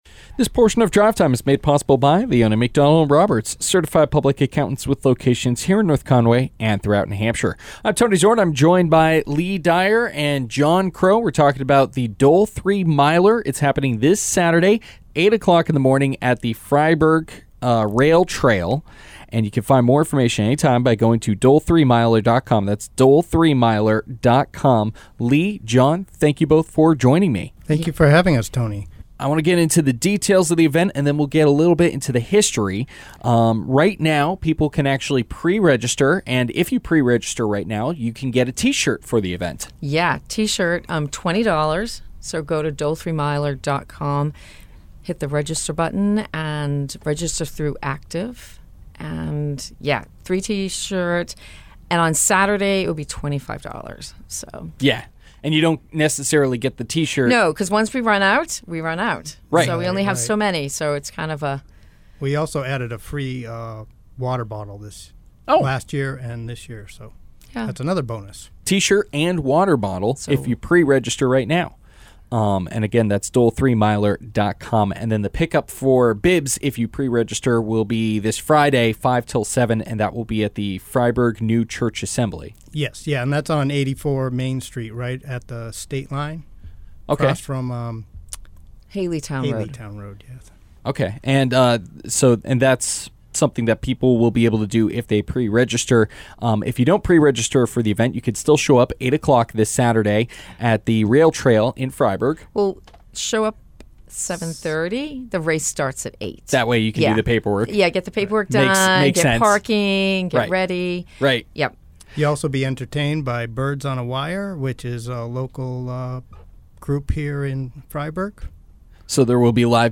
Drive Time Interviews are a specialty program on week days at 5pm where local not for profit organizations get a chance to talk about an upcoming event on air.